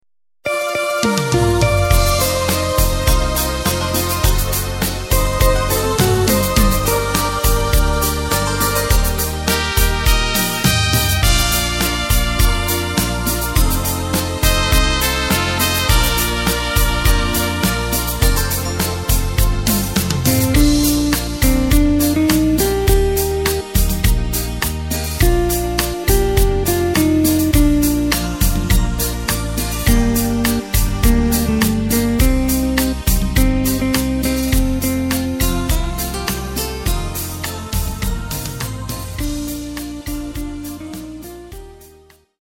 Takt:          4/4
Tempo:         103.00
Tonart:            Bb
Niederländischer Schlager aus dem Jahr 2008!